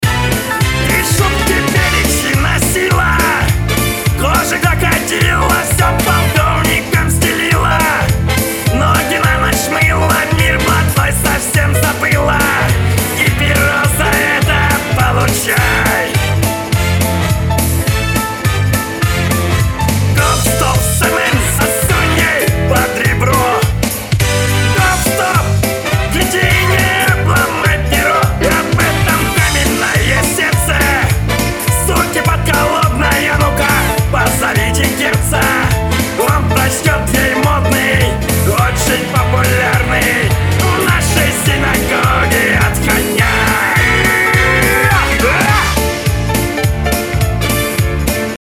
Вокал не "сидит" в минусе.